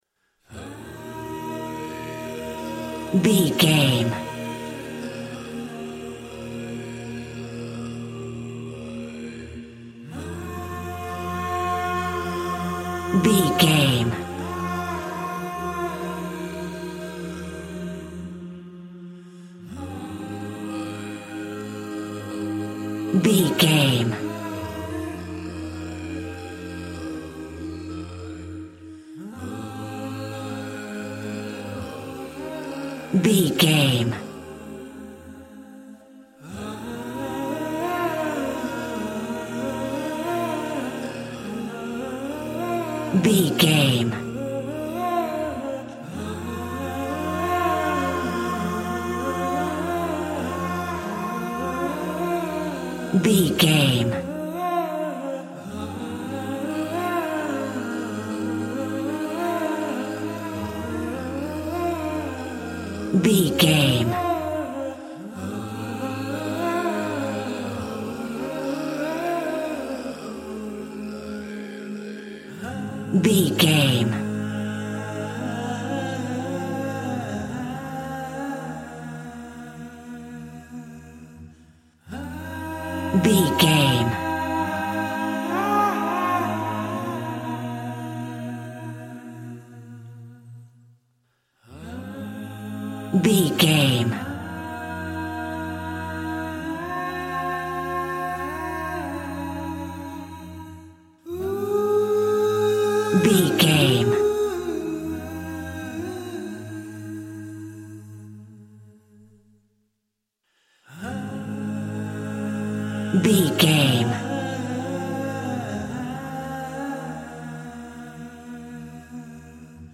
Ionian/Major
A♭
groovy
inspirational